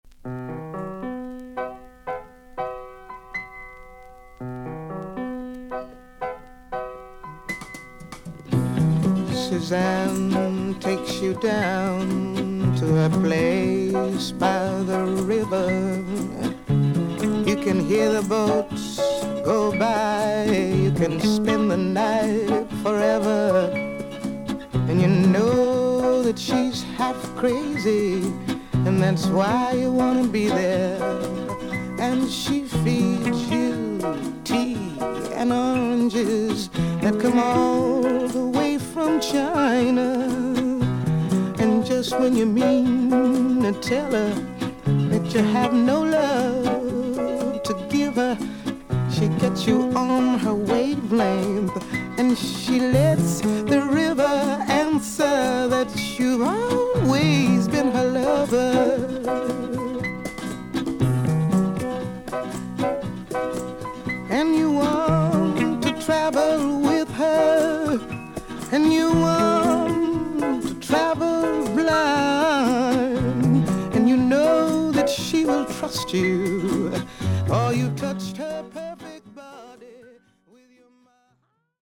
少々軽いパチノイズの箇所あり。少々サーフィス・ノイズあり。クリアな音です。
女性シンガー/ピアニスト。